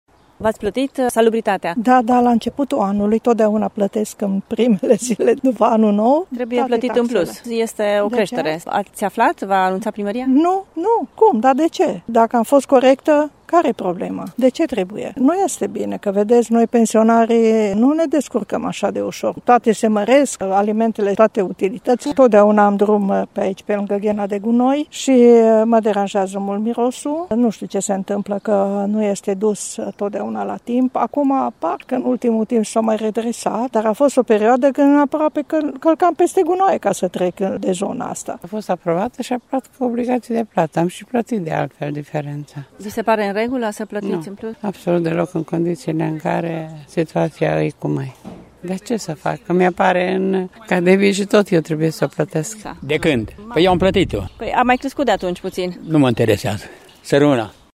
Unii târgumureșeni au achitat deja aceste majorări, în timp ce alții nici nu vor să audă de noi taxe, mai ales că municipiul Târgu-Mureș a fost sufocat de gunoaie anul acesta: